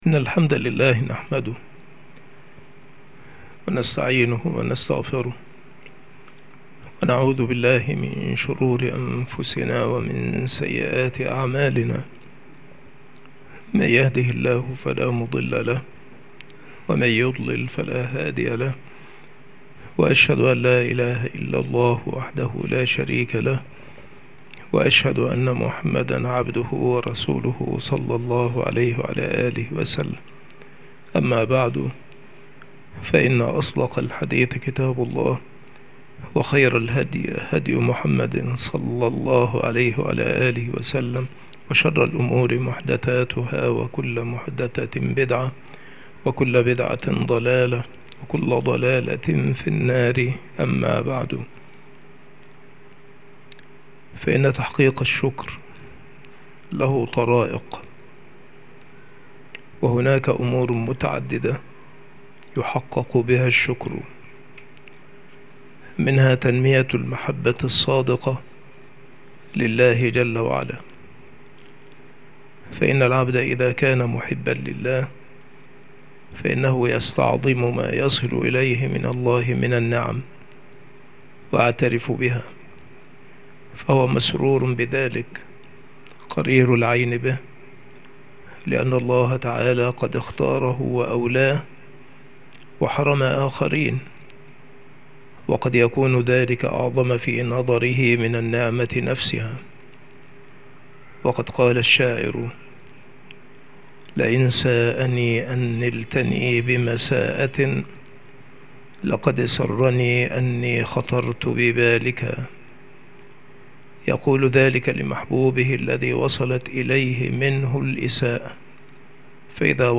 المحاضرة
مكان إلقاء هذه المحاضرة المكتبة - سبك الأحد - أشمون - محافظة المنوفية - مصر عناصر المحاضرة : طرق تحصيل الشكر.